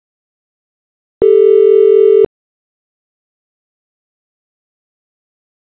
When the two pitches get close to a JND, they start to create auditory beat frequencies.
FIGURE 1.13. Variation of pitch in relationship to an in tune pitch.